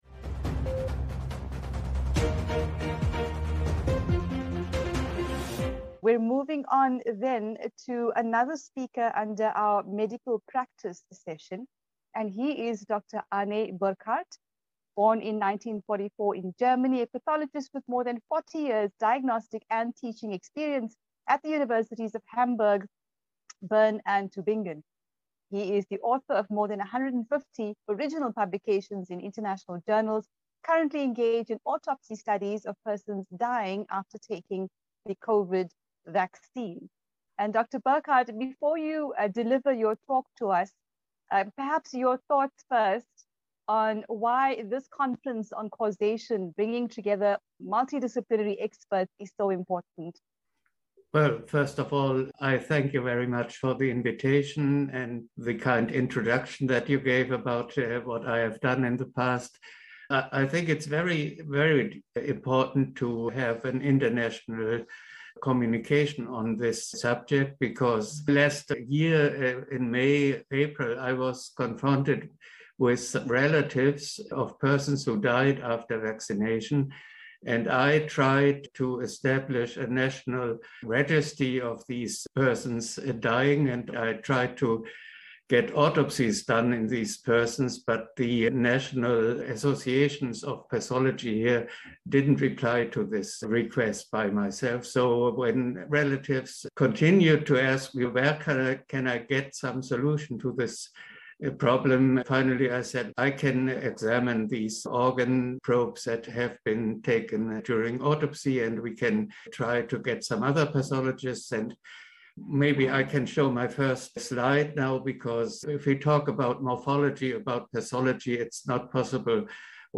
Autopsies: Evidence for Jab Related Harm and Death The inaugural Understanding Vaccine Causation Conference